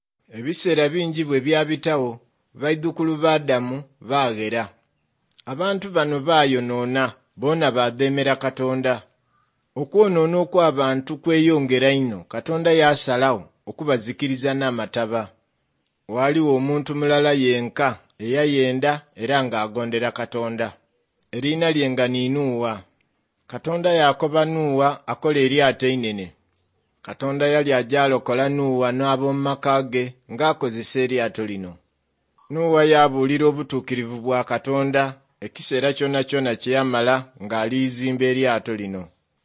23 August 2015 at 6:48 pm The accent sounds just like Kreyol I heard in Guadeloupe, but I don’t recognize any of the words as coming from French, so I’m going to guess something from West Africa.
The nasal vowels could be inherent to that language and not necessarily taken from French.